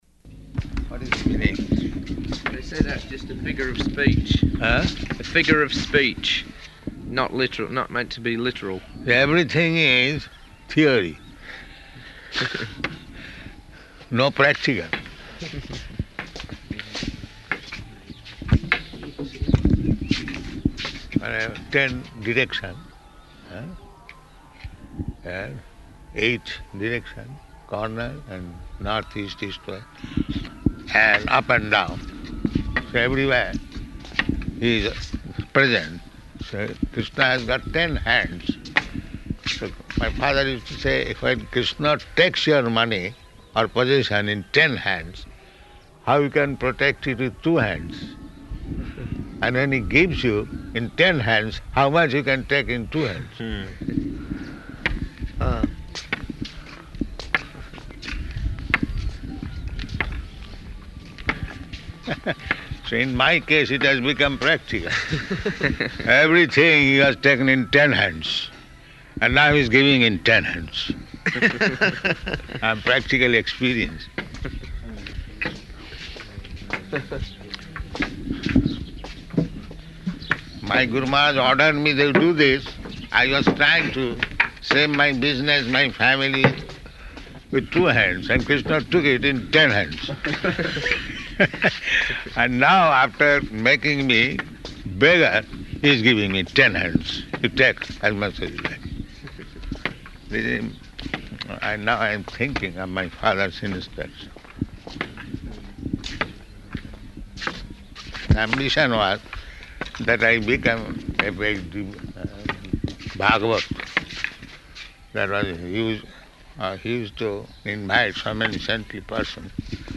Morning Walk --:-- --:-- Type: Walk Dated: February 10th 1976 Location: Māyāpur Audio file: 760210MW.MAY.mp3 Prabhupāda: What is this meaning?